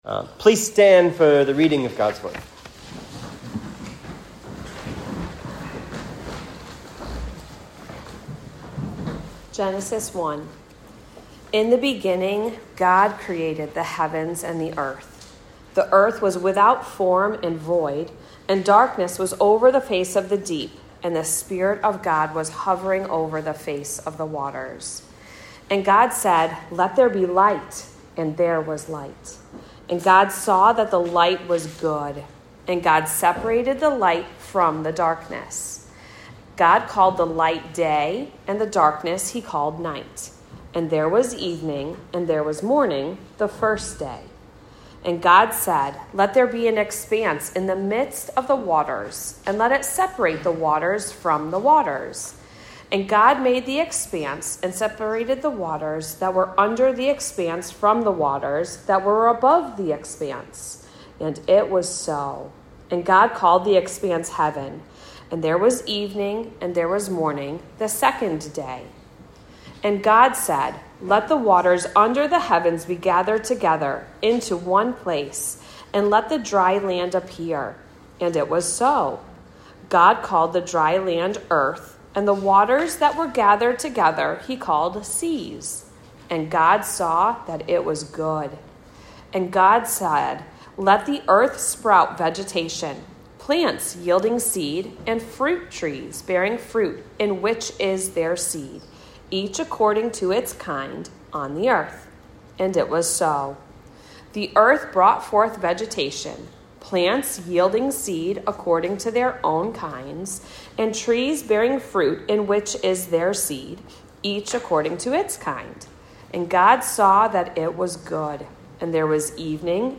Genesis 1 Sermon Outline “Creation of the World”
(Preached at Cross of Christ Fellowship in Naperville on 9/7/2025)